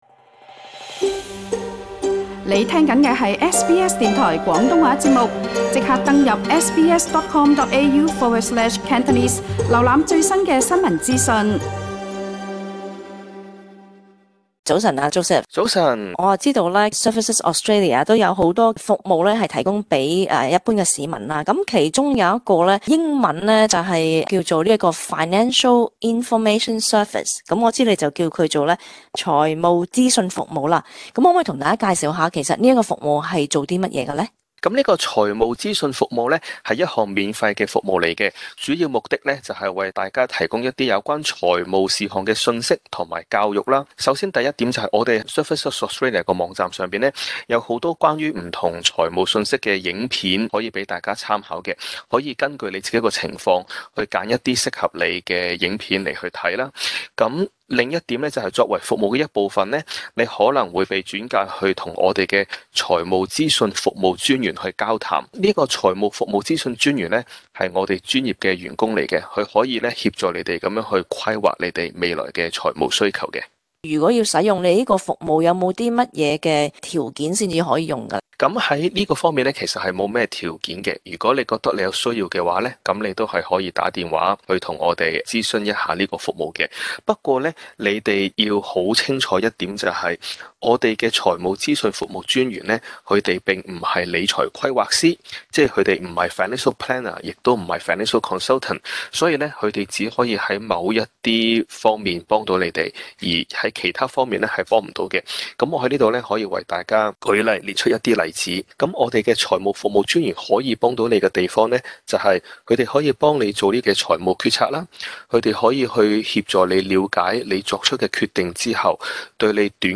至於其他詳情，請收聽這節【社區專訪】。